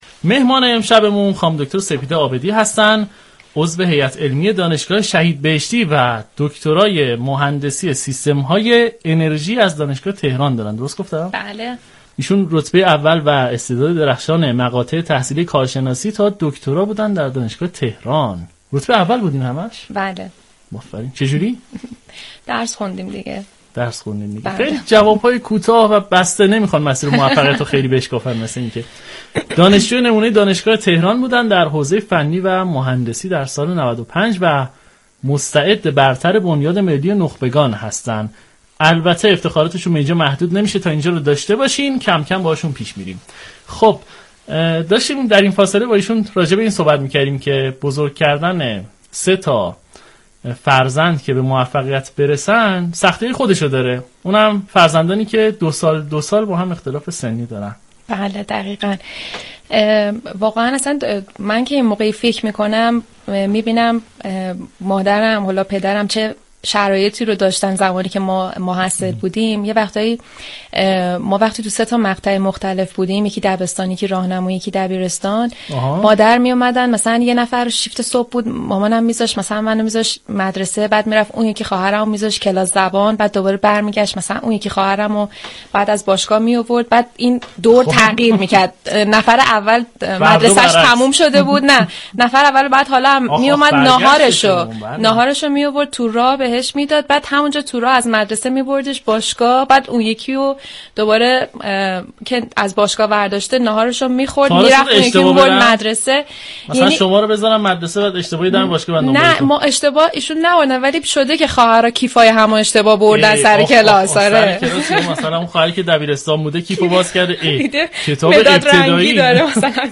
در ادامه گلچین این گفتگو را بشنویم .